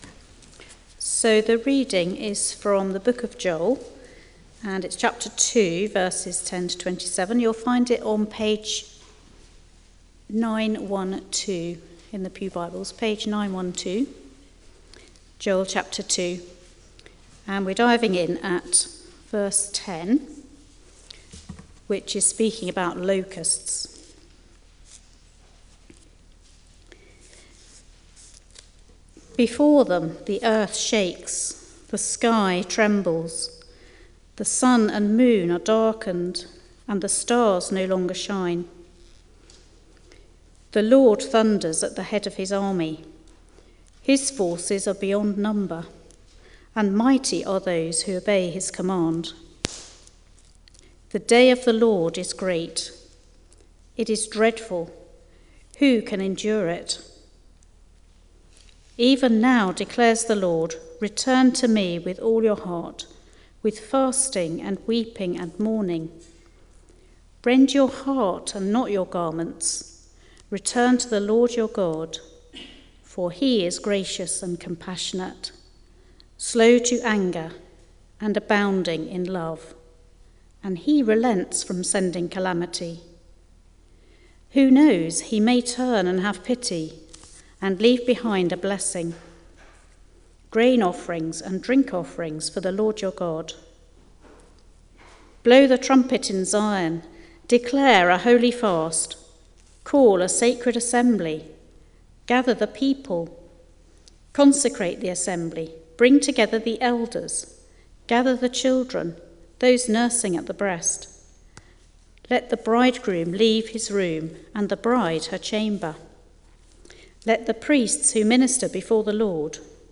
Media for Arborfield Morning Service on Sun 28th Oct 2012 10:00 Speaker
Judgment approaches Sermon All music is licensed by Podcast/RSS FEED The media library is also available as a feed, allow sermons to be automatically downloaded to your PC or smartphone.